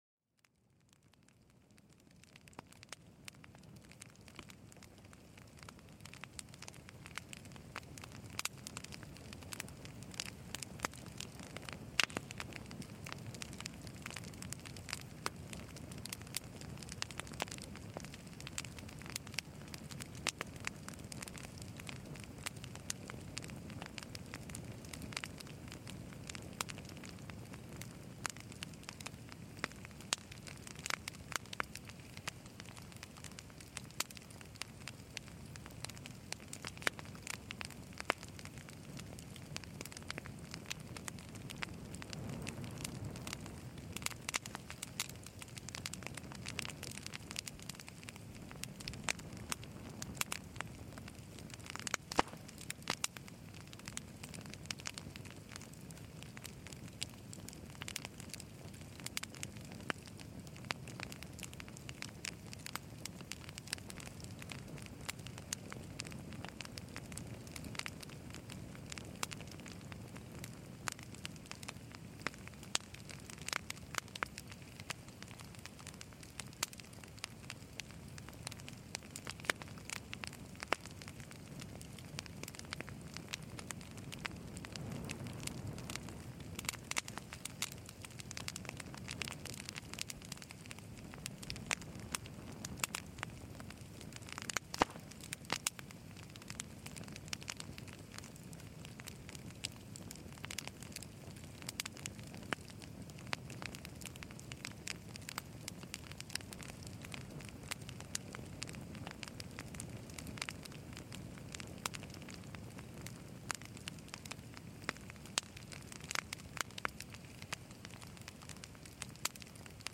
Douce chaleur : Le crépitement d’un feu de camp pour apaiser l’esprit